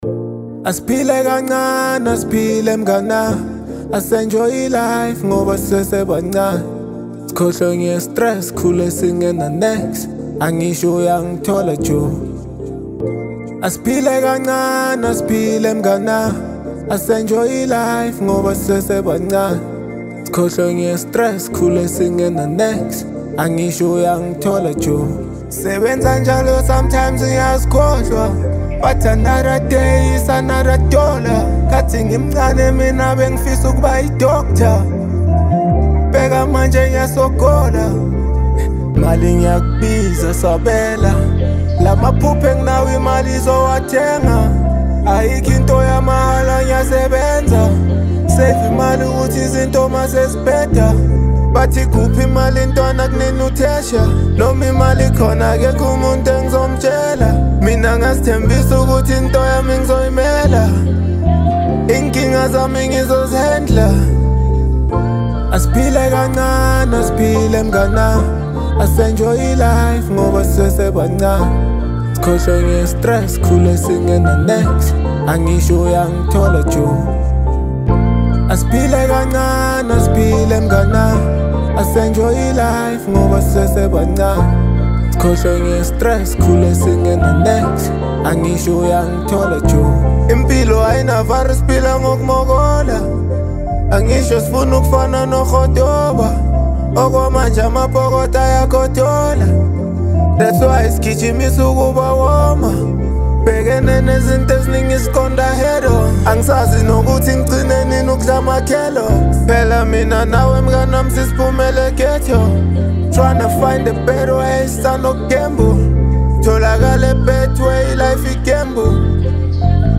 This track is a powerful anthem
a powerful and introspective track